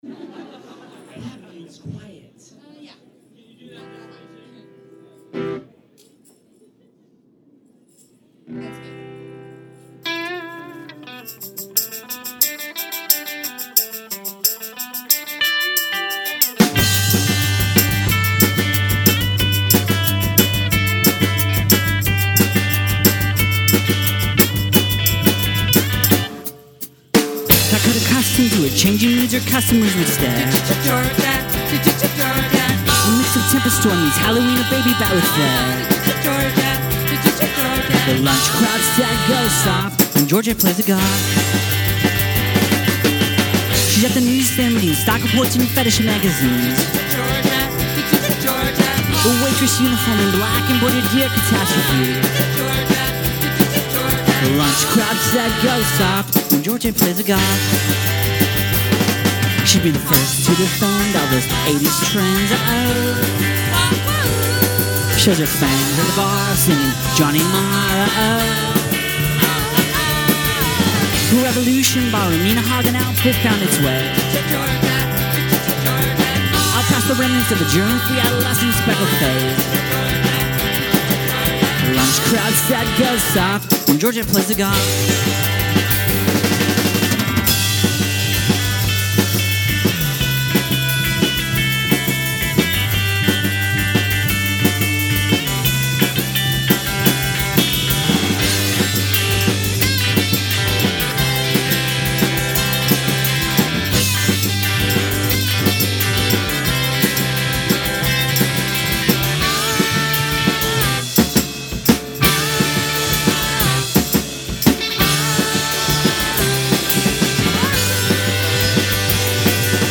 in front of a (slightly larger) small audience.